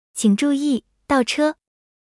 reverse_gear.wav